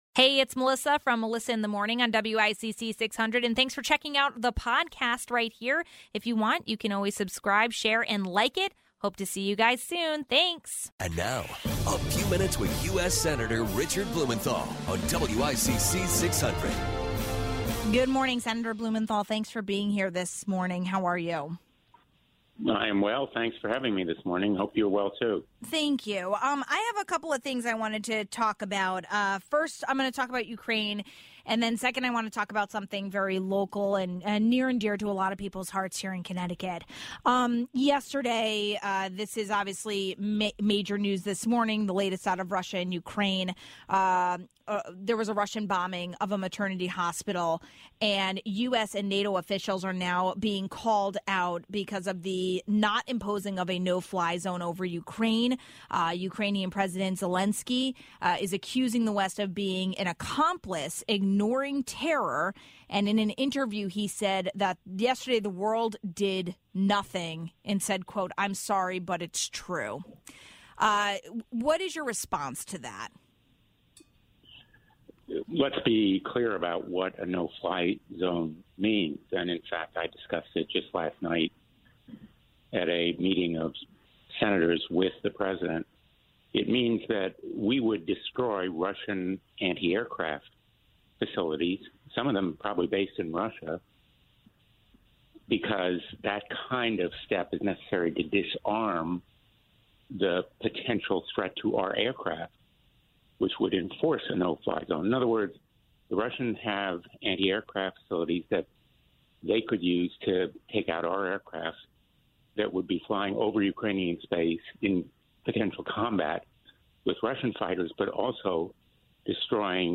Stop mentioning barrels of oil, what about using US resources to bring gas prices down? Senator Richard Blumenthal calls that an excellent question then explains why it comes down to the oil companies making that decision.
We talked to an expert from University of New Haven about that.